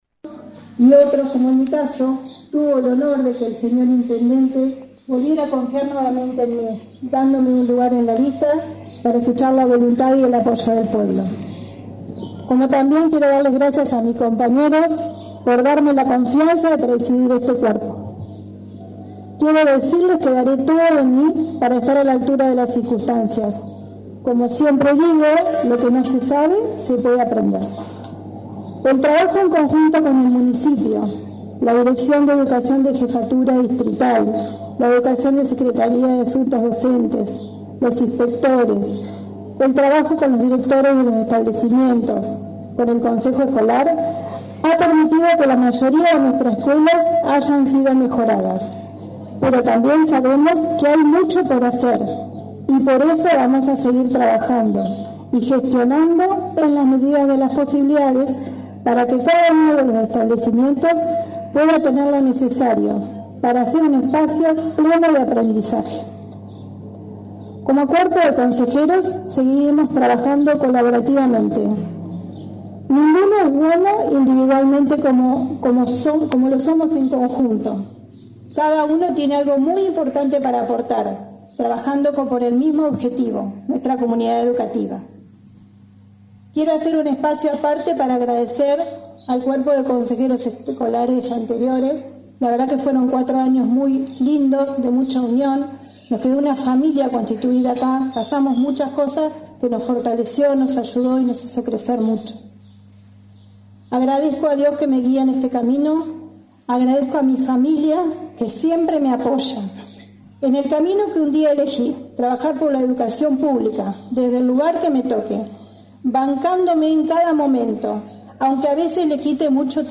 En la mañana de este jueves se llevó a cabo la ceremonia de asunción y juramento de los nuevos consejeros escolares electos el pasado 22 de octubre para el período 2023-2027. Dicho acto tuvo lugar en el veredón ubicado sobre calle Harosteguy, actual sede del edificio del Consejo Escolar.